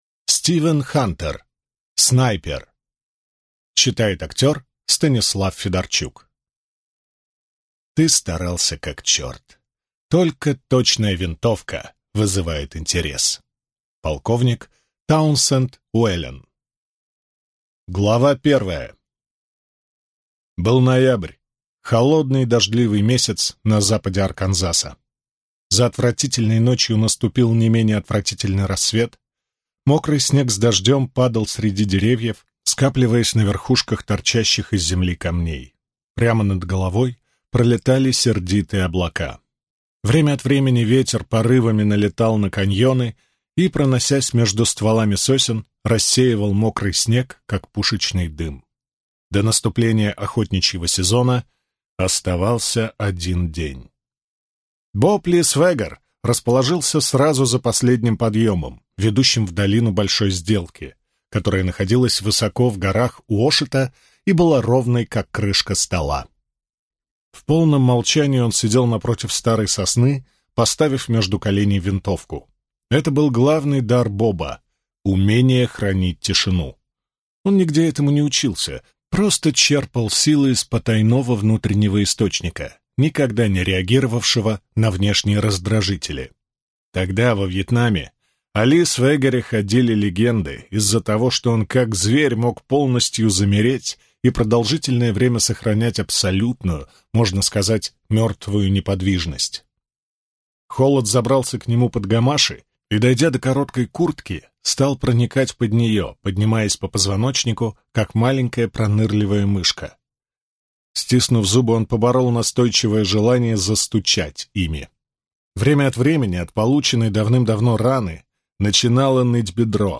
Аудиокнига Снайпер | Библиотека аудиокниг